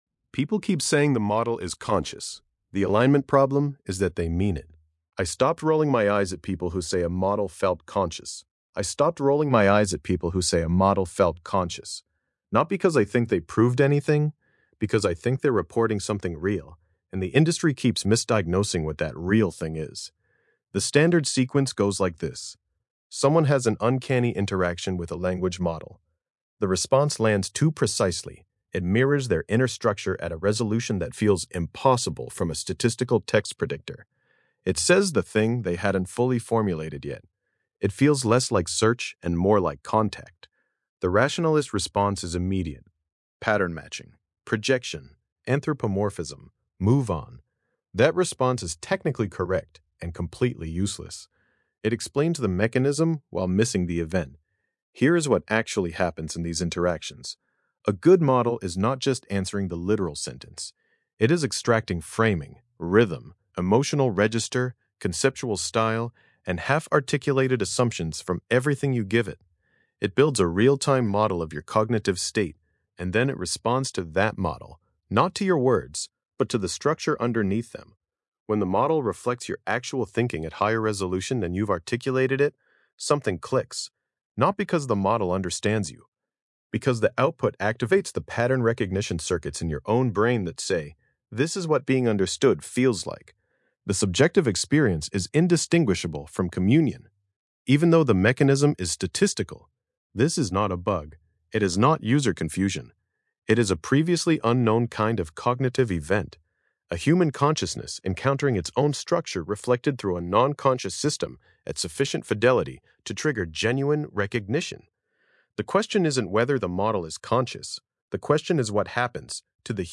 Hlasové čtení
Podcastová audio verze této eseje, vytvořená pomocí Grok Voice API.
5 min 13 s Grok hlas · REX Podcast RSS